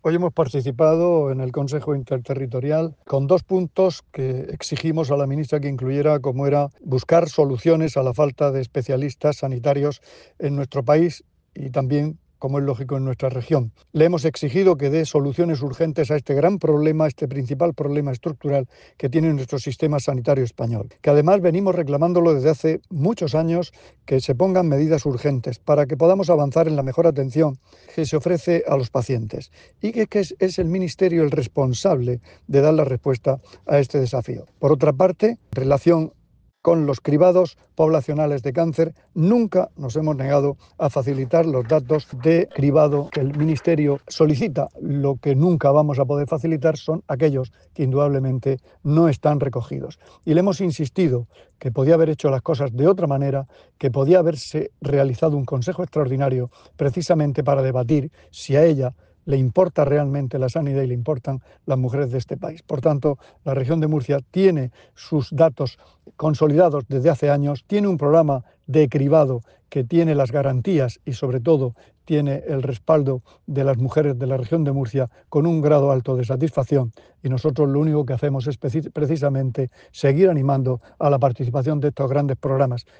Sonido/ Declaraciones del consejero de Salud tras su participación en el Consejo Interterritorial de Salud.
La reunión del Consejo Interterritorial del Sistema Nacional de Salud se celebró hoy en la sede del Ministerio de Sanidad en Madrid.